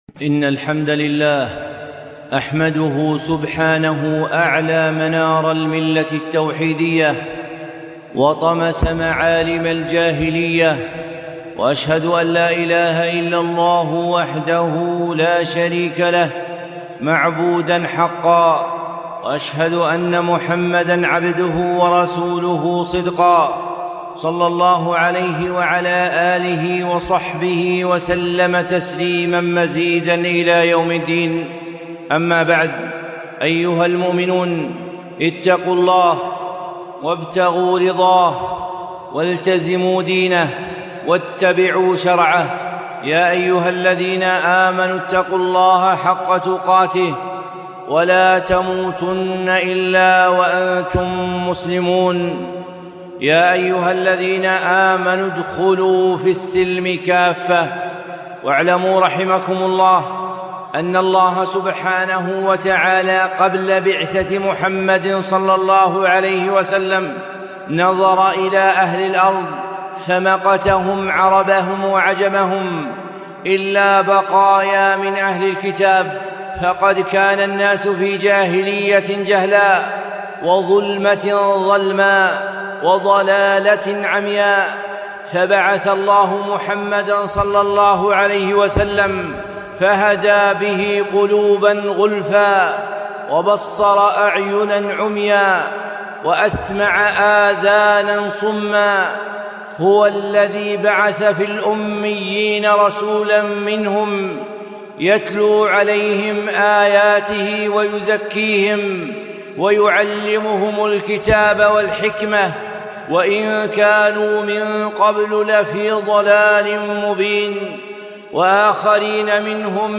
خطبة - إن الله رضي لكم ثلاثا فاغتنموها